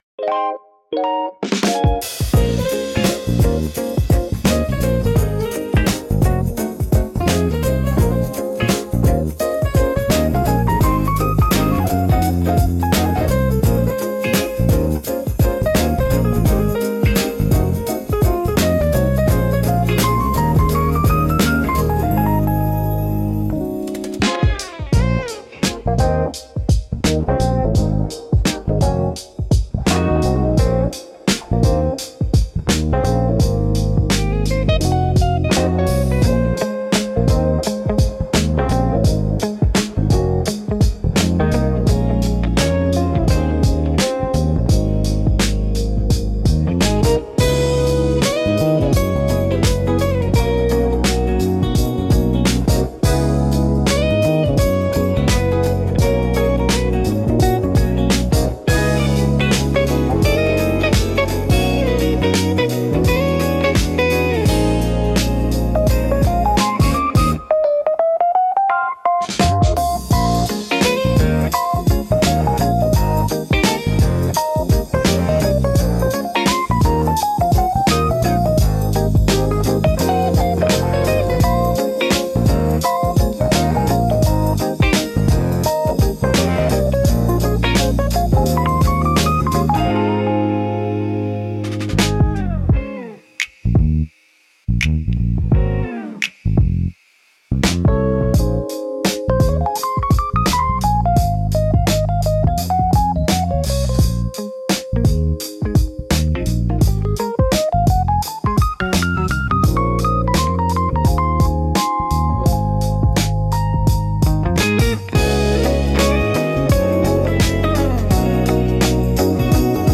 Warm Lo-Fi Mood